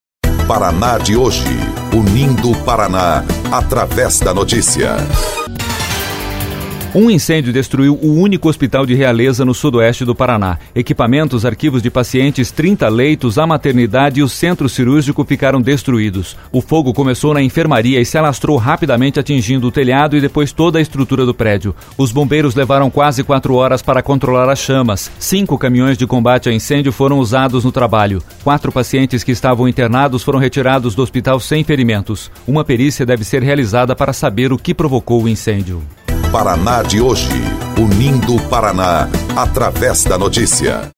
BOLETIM - Único hospital de Realeza, no sudoeste, é destruído por incêndio